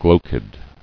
[glo·chid]